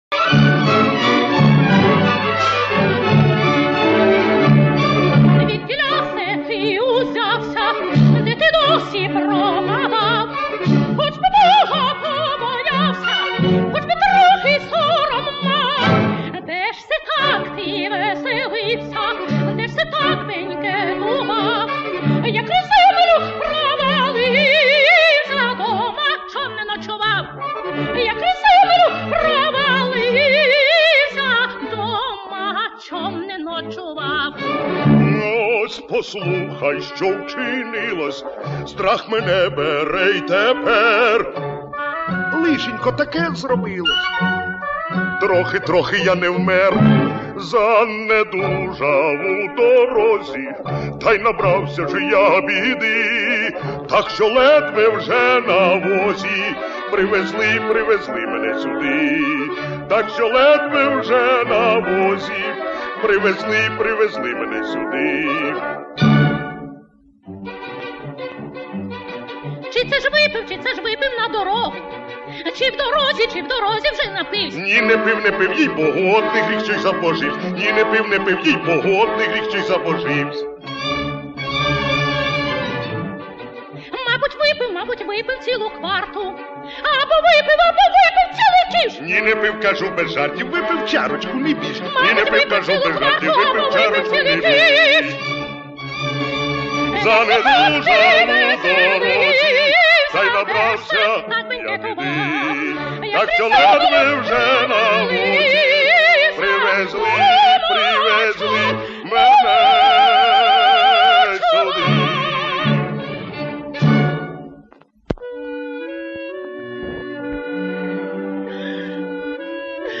Дует Одарки та Карася з опери